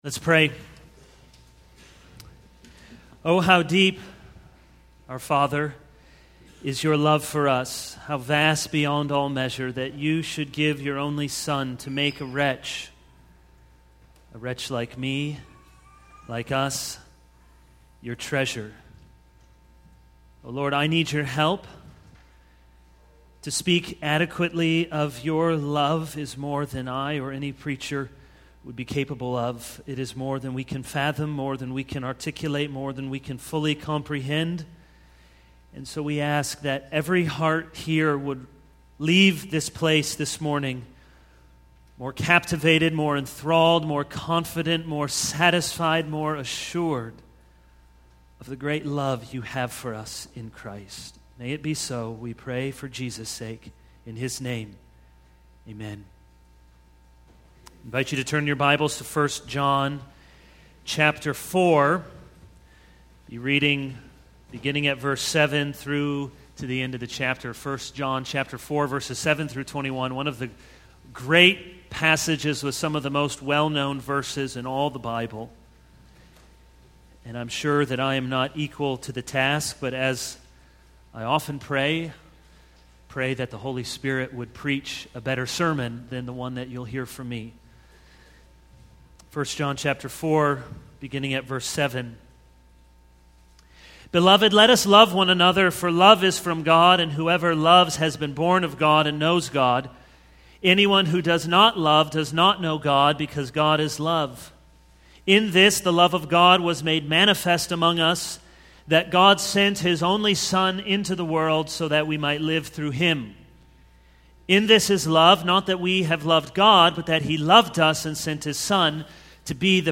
This is a sermon on 1 John 4:7-21.